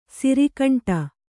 ♪ siri kaṇṭa